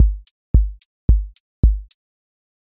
QA Test — four on floor
four on floor QA Listening Test house Template: four_on_floor April 17, 2026 ← Back to all listening tests Audio four on floor Open MP3 directly Selected Components macro_house_four_on_floor voice_kick_808 voice_hat_rimshot Test Notes What This Test Is Four on floor Selected Components macro_house_four_on_floor voice_kick_808 voice_hat_rimshot